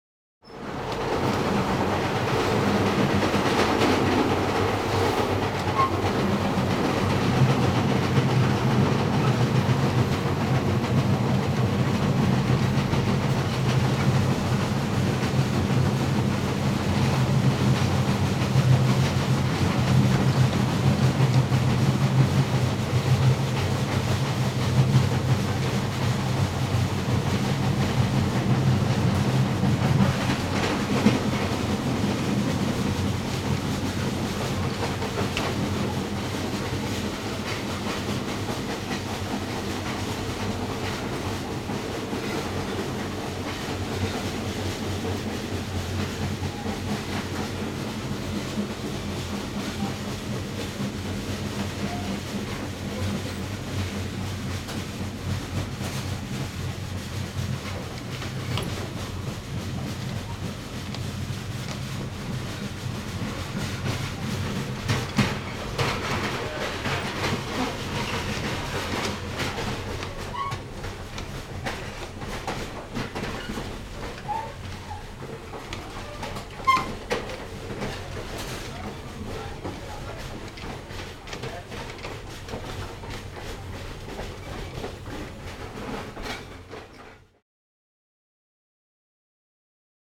transport
Train Passenger Exterior Onboard Train Slowing Down